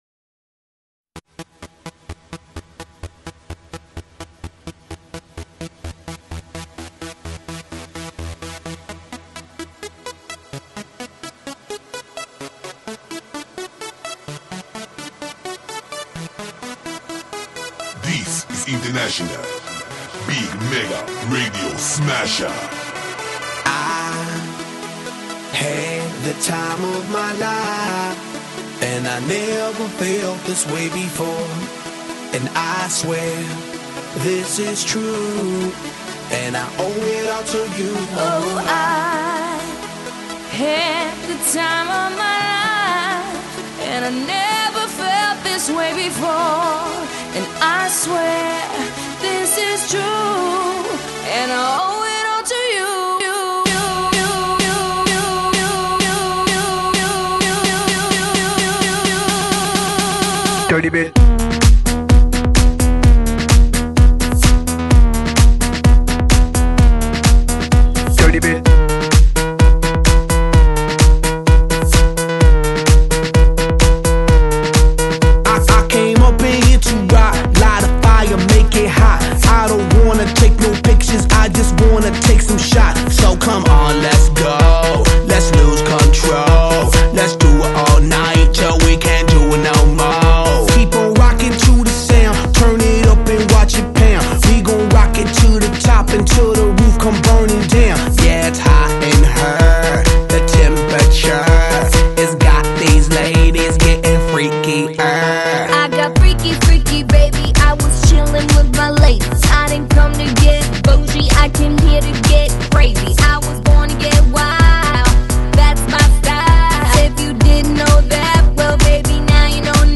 音乐风格: 流行
之前HIP HOP风格逐渐弱化，
取而代之的是欧陆舞曲节拍，